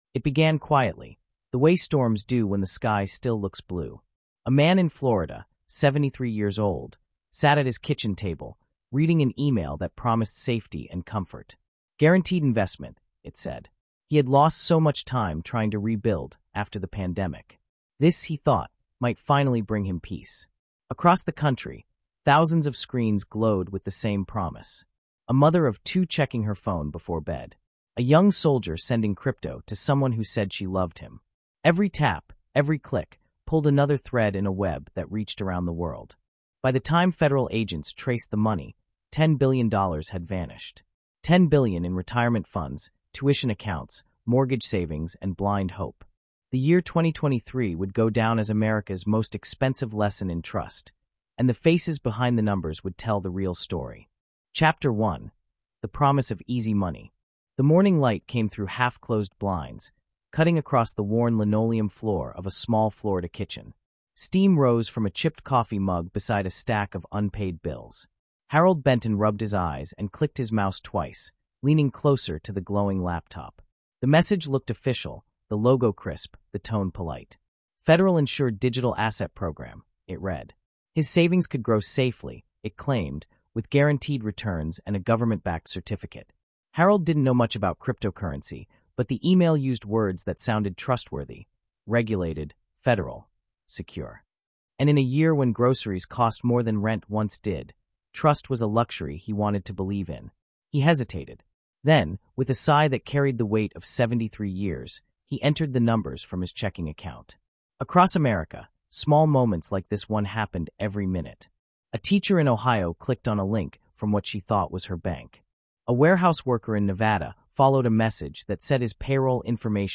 Consumers Lost $10 Billion to Fraud in 2023 is a cinematic, Shawshank-style true-crime drama inspired by real events. Told through the quiet humanity of its characters, it explores America’s largest financial deception of the digital age — the year when ordinary trust became a global currency for exploitation.